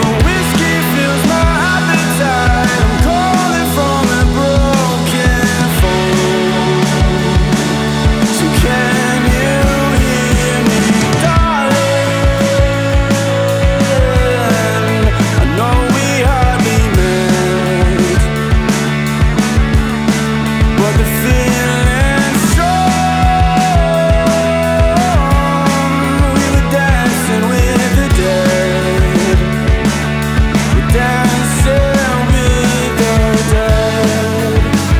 Жанр: Иностранный рок / Рок / Инди